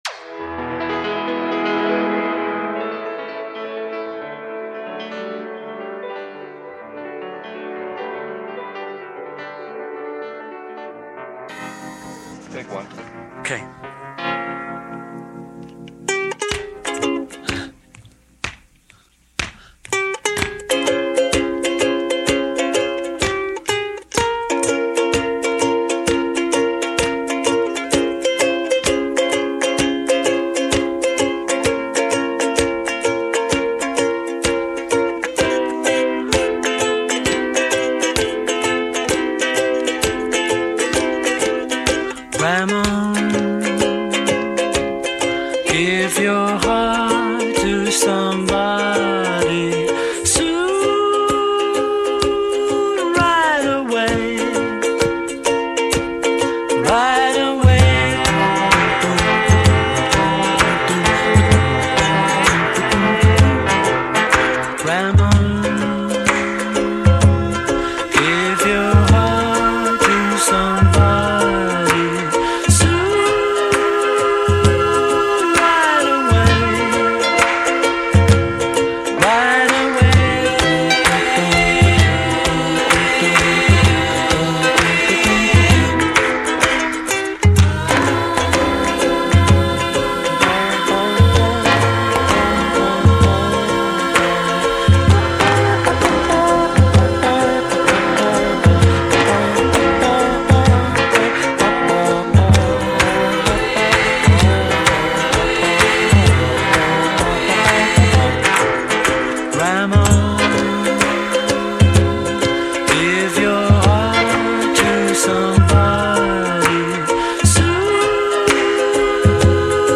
записанный в январе-марте 1971 года в RCA Studios, Нью-Йорк